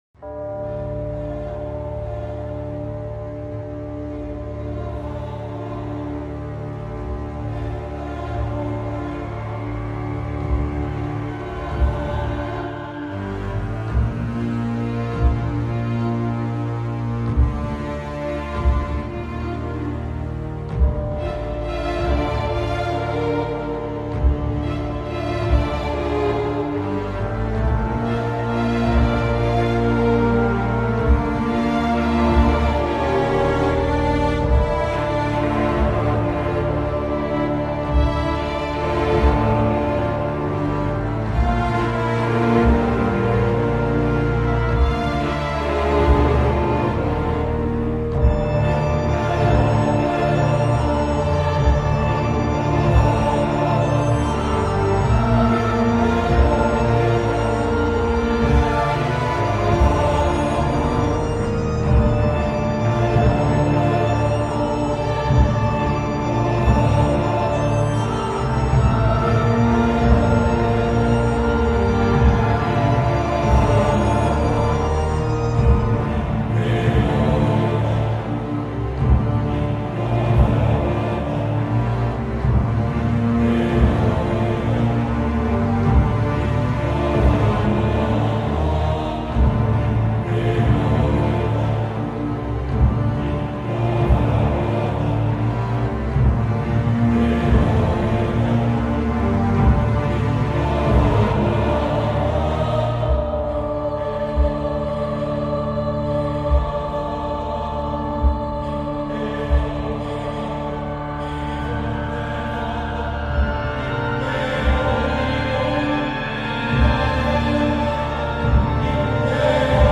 gp_horror_1.mp3